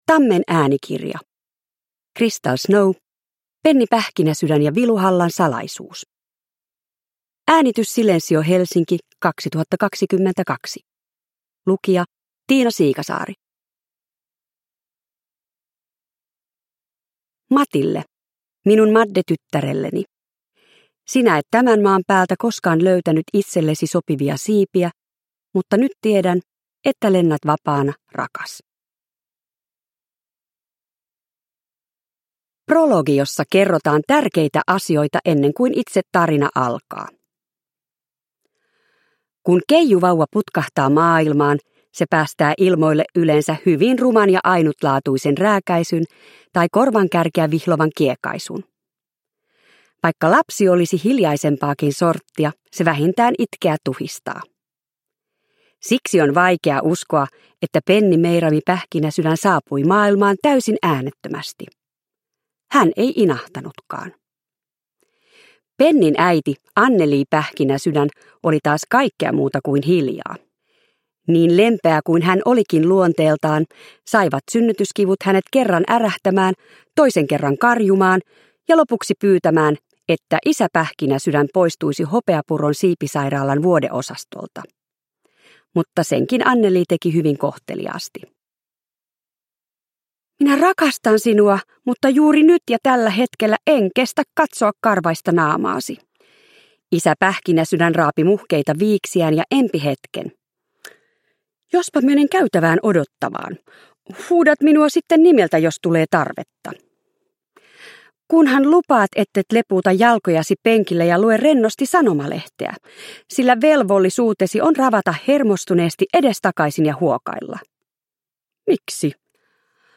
Penni Pähkinäsydän ja Viluhallan salaisuus – Ljudbok – Laddas ner